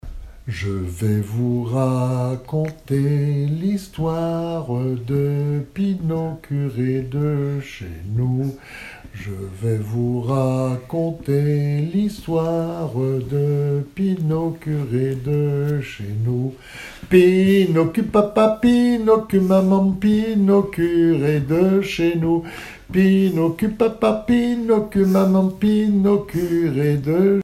Mémoires et Patrimoines vivants - RaddO est une base de données d'archives iconographiques et sonores.
Genre énumérative
Pièce musicale inédite